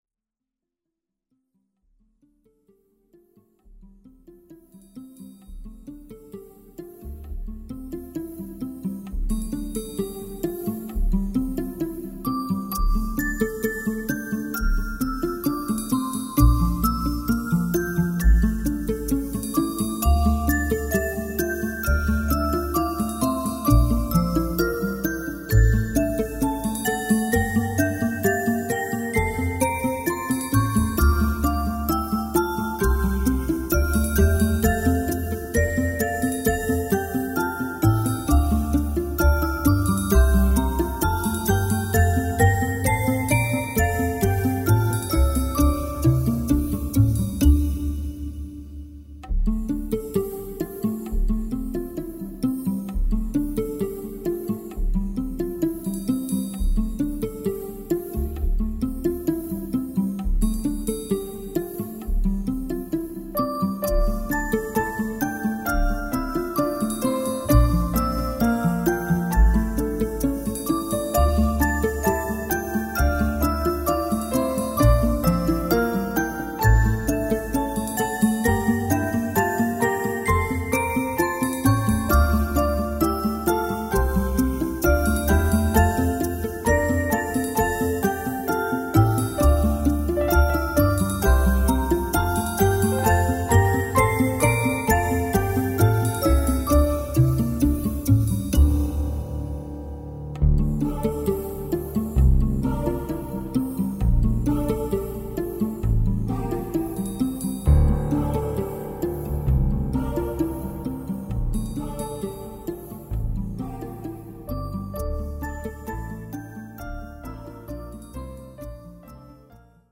music for the holiday season...
exquisite electronic and acoustic classics